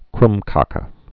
(krmkə, krŭmkāk)